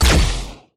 etfx_shoot_energy04.wav